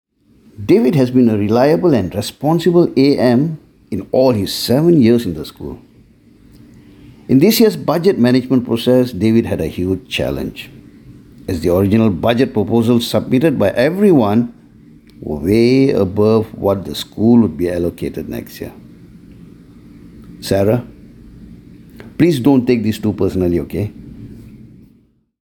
Voice Samples
male